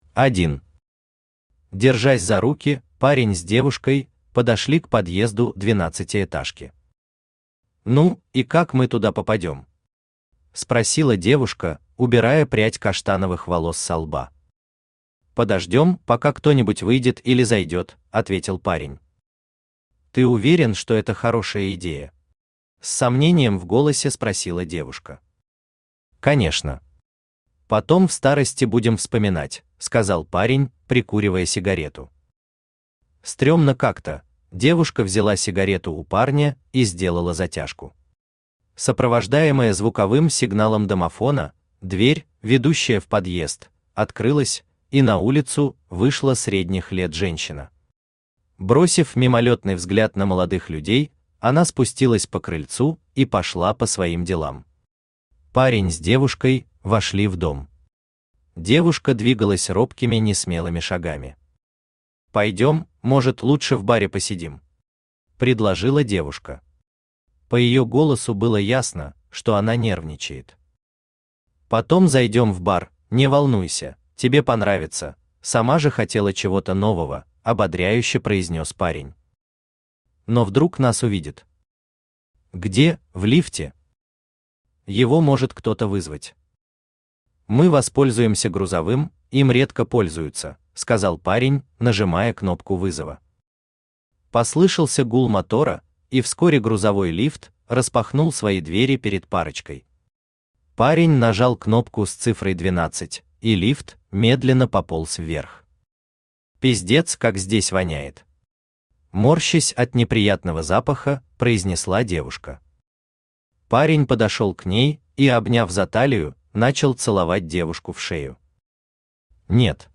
Aудиокнига SEX квест Автор Джон Кор Читает аудиокнигу Авточтец ЛитРес.